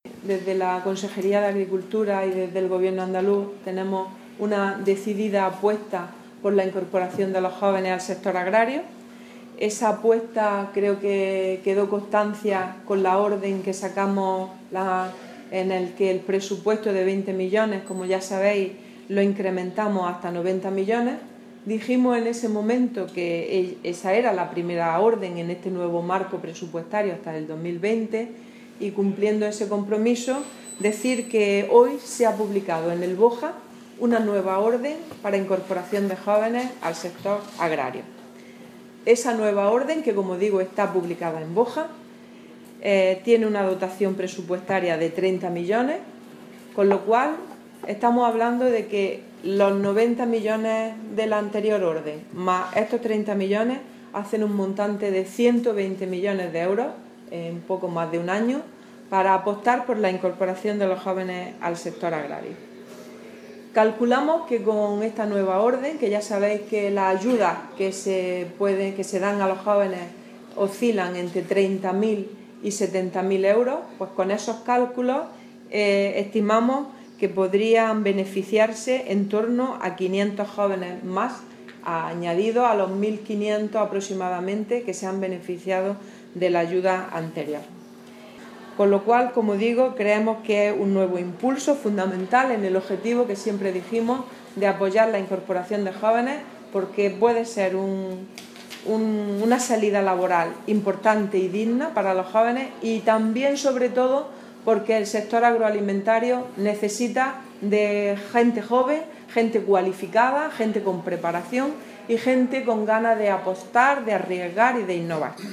Durante la rueda de prensa celebrada en el centro del Instituto de Investigación y Formación Agraria y Pesquera de Andalucía (Ifapa) ubicado en La Mojonera (Almería), Carmen Ortiz ha animado a pedir estas subvenciones a los solicitantes de la anterior convocatoria que no resultaron beneficiarios y cumplen las condiciones para poder acceder a los incentivos.
Declaraciones de Carmen Ortiz sobre ayudas para la incorporación de jóvenes a la actividad agraria